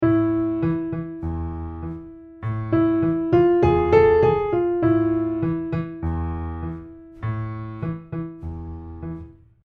これを『和声的短音階』と呼びます。
ほのかにアラビックな感じと言いますか、エスニックといいますか…
中東な響きしませんか…？？
これがまた何というか…大変民族のかほりのする音程なんですーー！
ね、あっというまに蛇とか出てきそうな雰囲気に…笑